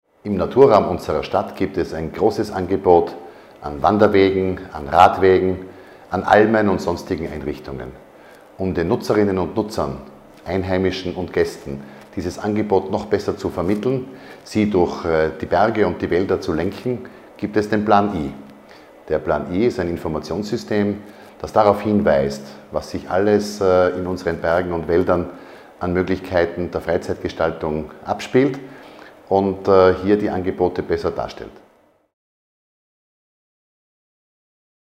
O-Ton von Vizebürgermeister Christoph Kaufmann